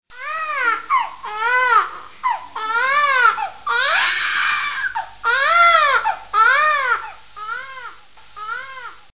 File Type : Funny ringtones
Description: Download crying baby mp3 ringtone.